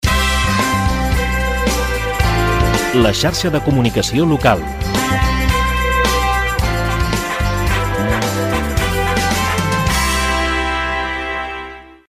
Indicatius